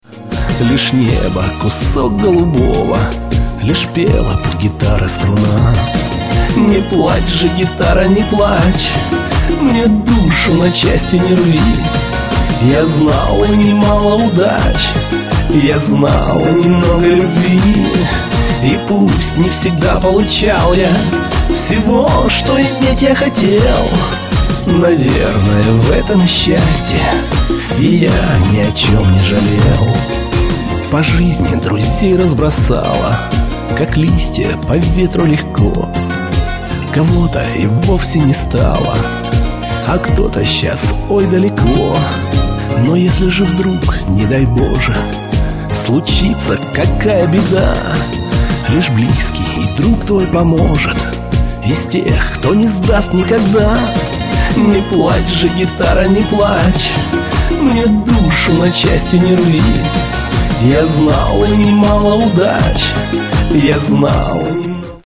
• Качество: 256, Stereo
русский шансон